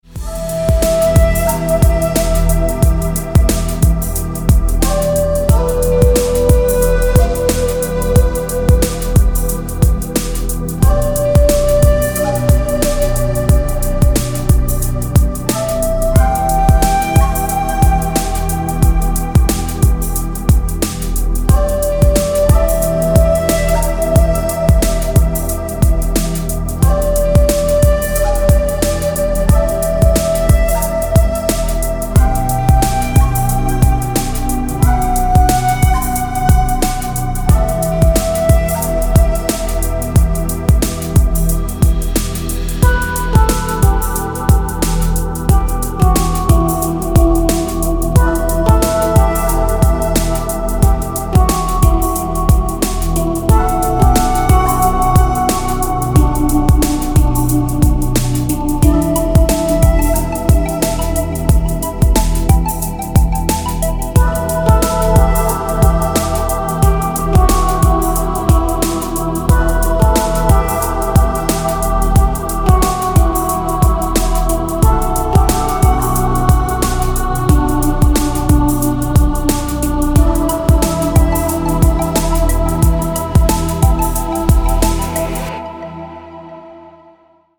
Спокойные звонки, спокойные рингтоны